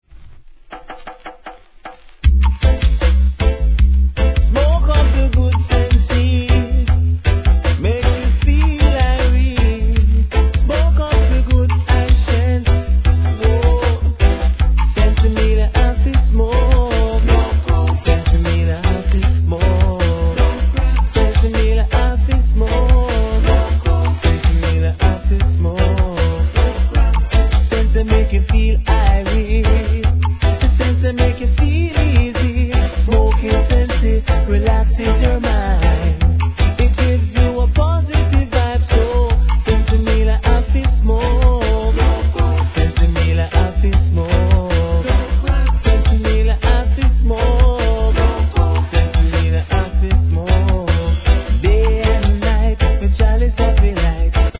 REGGAE
一流のコーラス・ワークでSWEETナンバーから怒渋ナンバーまで聴かせます!